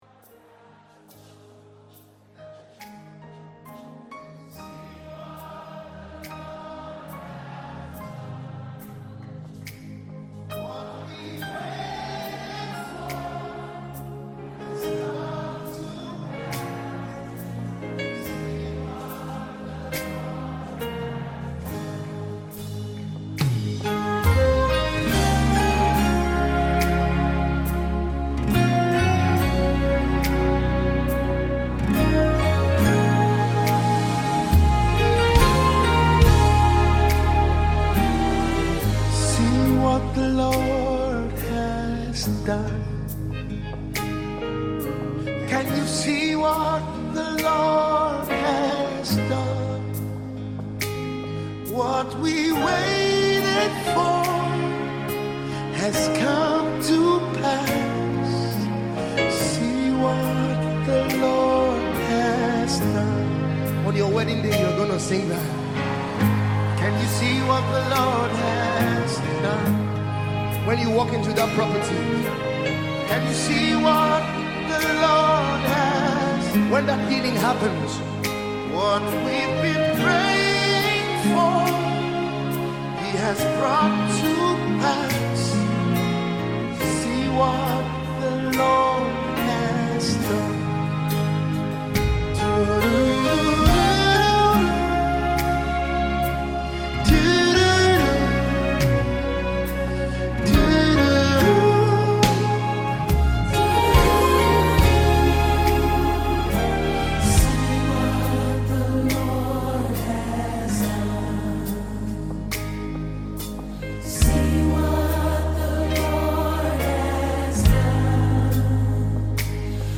Famous Nigerian gospel minister